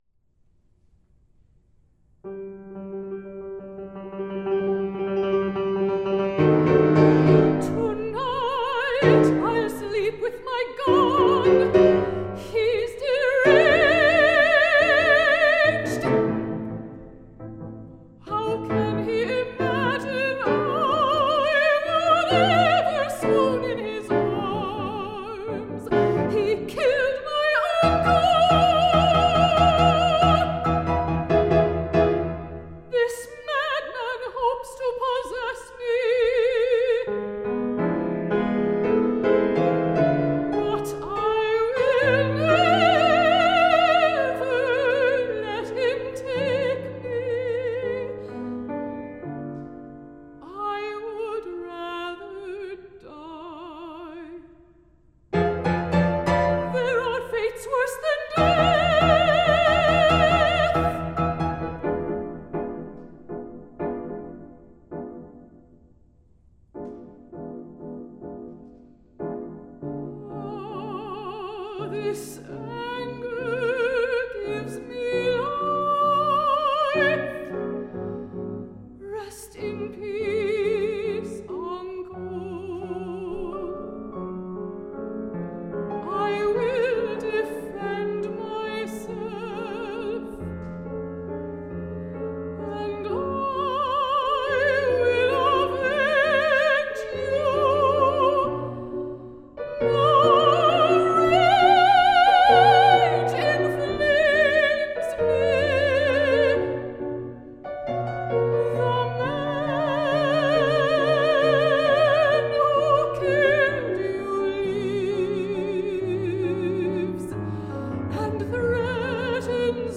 mezzo soprano
piano